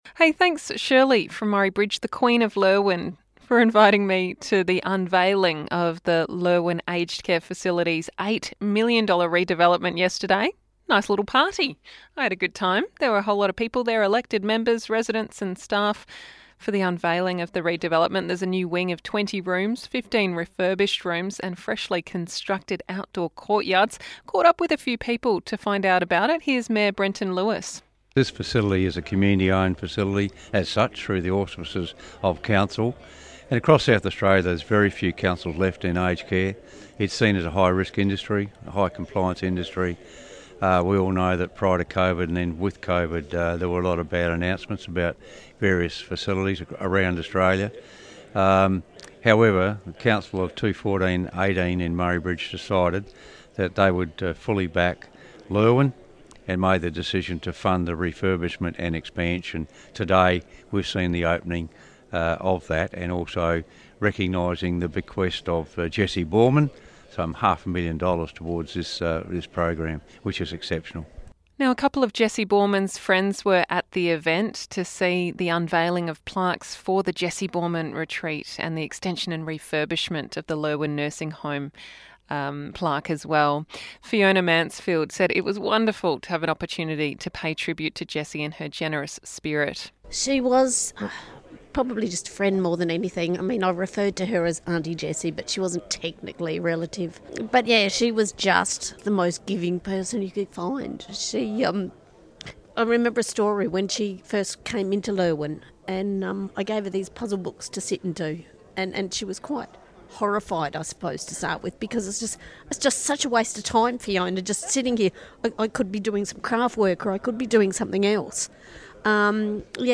Murray Bridge event